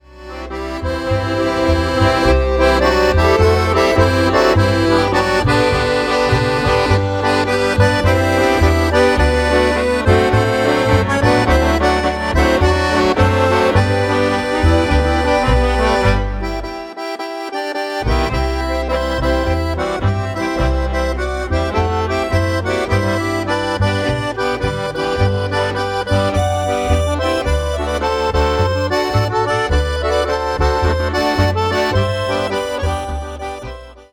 Slow